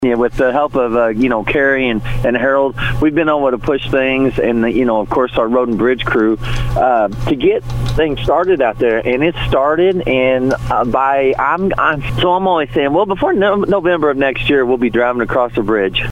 St. Francois County Commissioner, David Kater, says the rebuilding of the bridge couldn't have begun without the assistance of the other commissioners and many people in several departments.